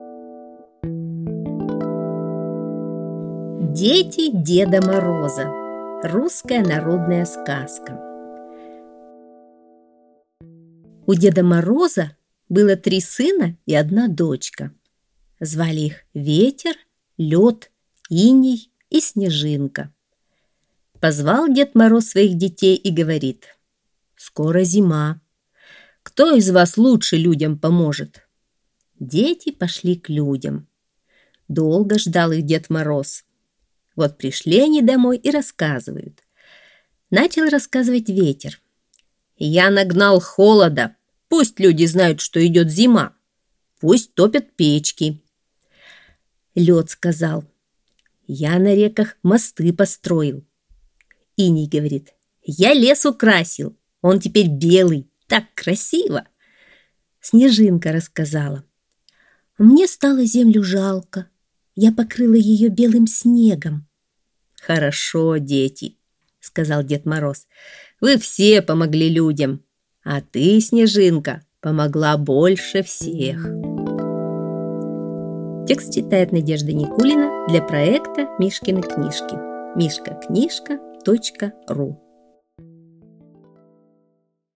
Дети деда Мороза – русская народная аудиосказка